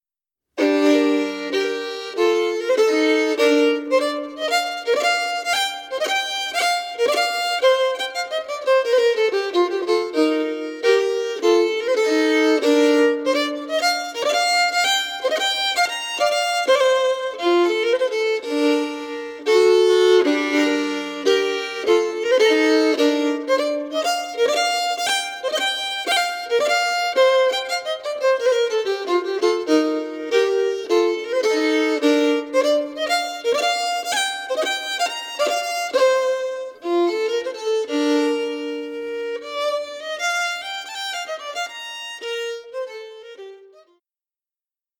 strathspey